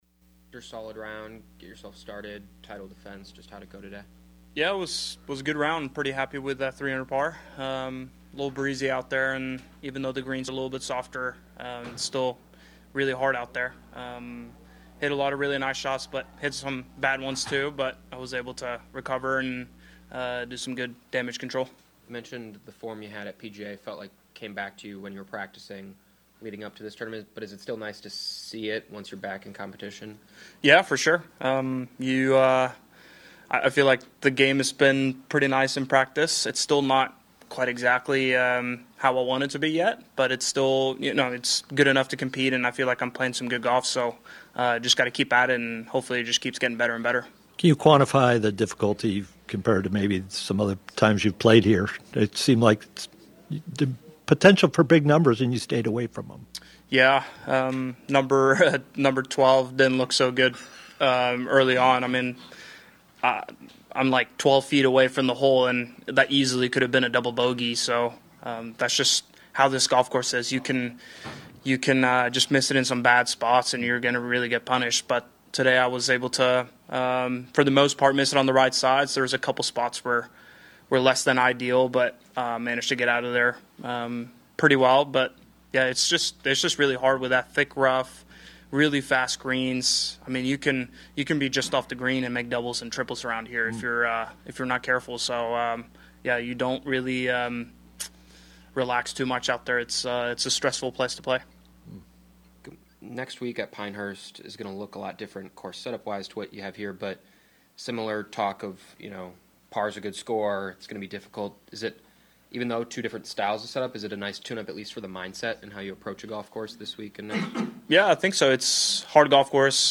Viktor Hovland defending champion Memorial Tournament 1st Round News Conference Tied for 7th with four other golfers June 6, 2024.mp3